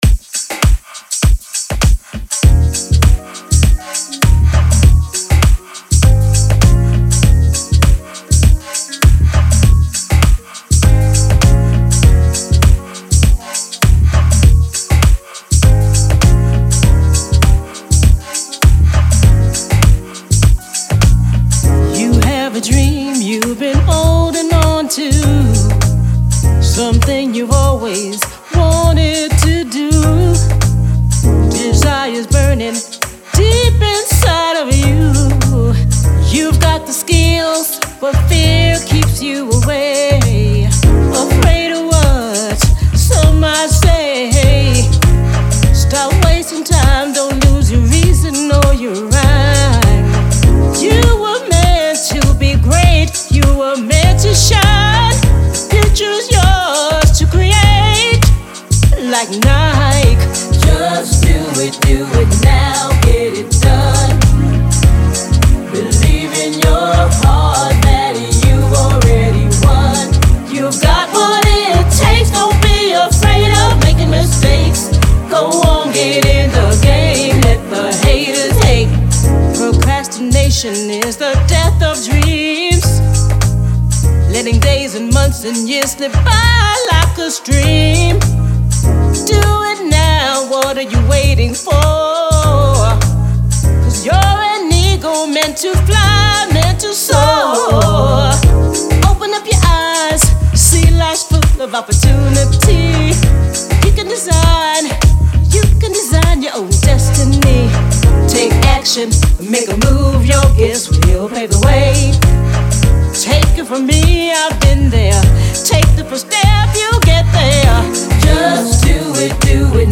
creatively fuses the R&B, jazz, pop and soul genres
jazzy, mid-tempo tune